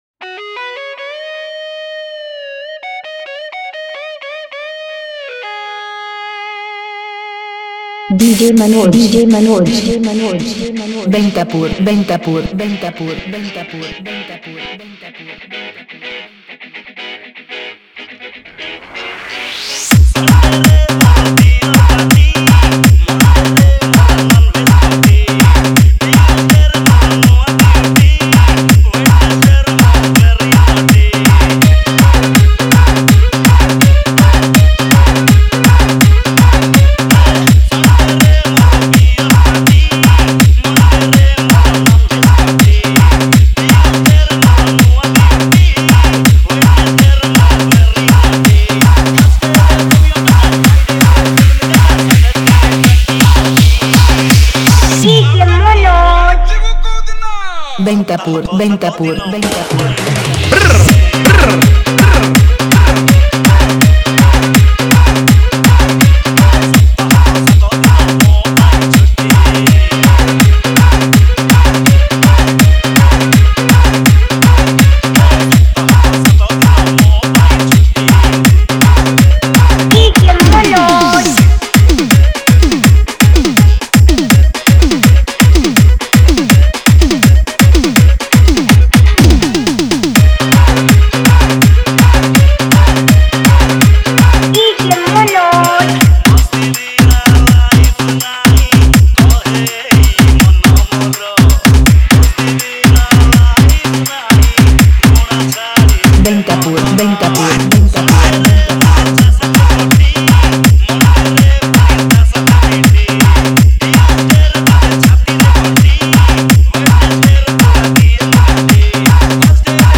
• Category:Odia New Dj Song 2017